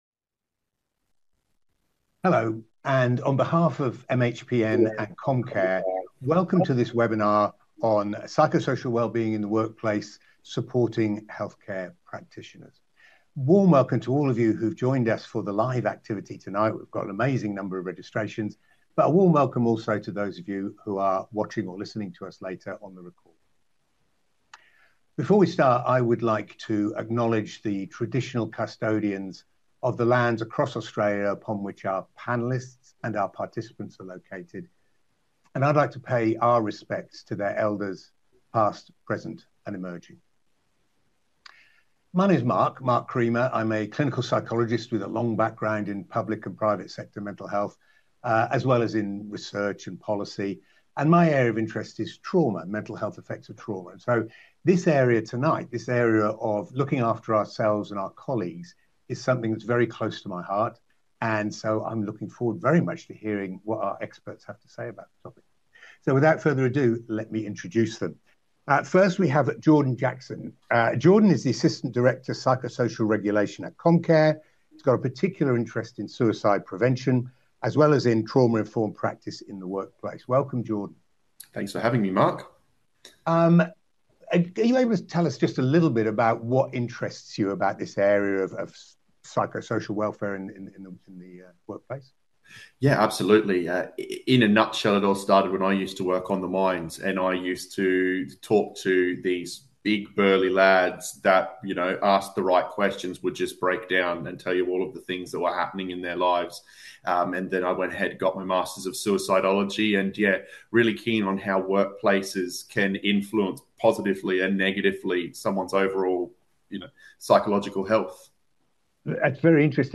Healthcare professionals often work in busy, high-pressure, and emotionally demanding environments. At this webinar, our expert multidisciplinary panel share practical insights on spotting early signs of stress in yourself and colleagues, and offer strategies and tools you can use straight away to support wellbeing in your workplace.